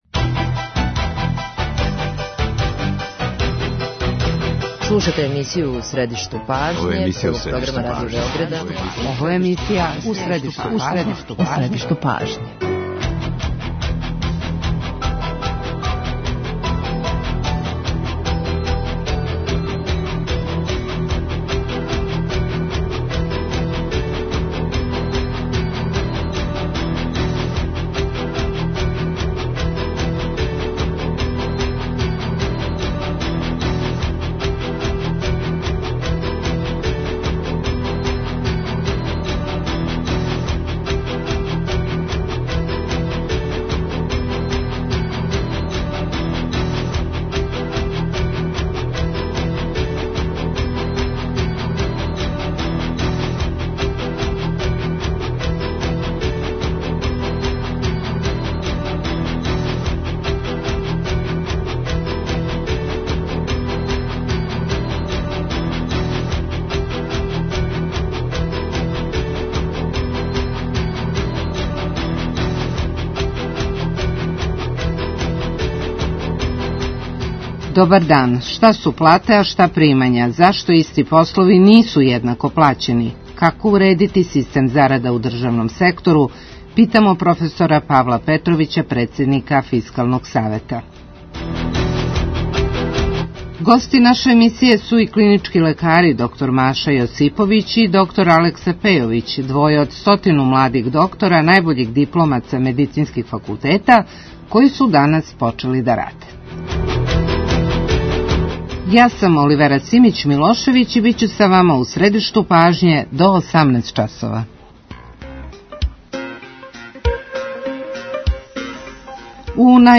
Шта су плате, а шта примања? Зашто исти послови нису једнако плаћени - питамо др Павла Петровића, председника Фискалног савета.
Гости наше емисије су и млади лекари који од данас почињу да раде.